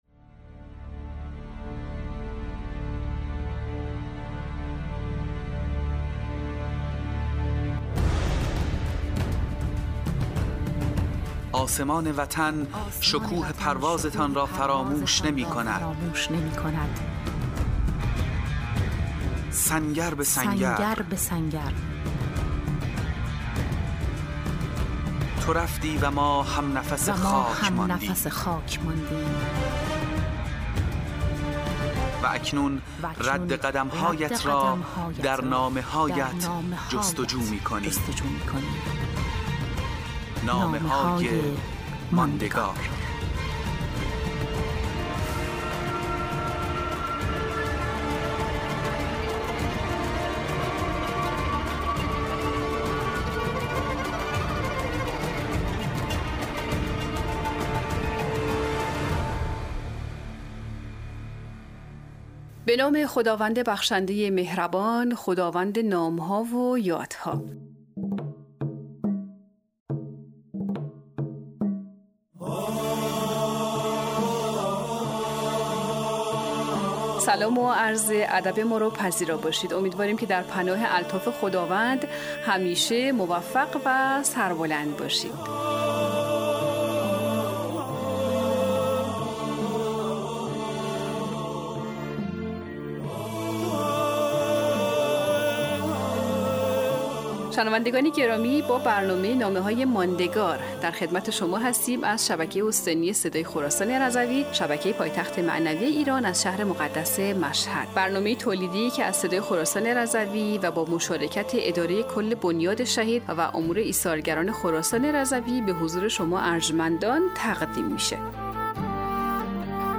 مجموعه 25 برنامه رادیویی از شهدای استان خراسان رضوی ( سری دوم ) - شماره 7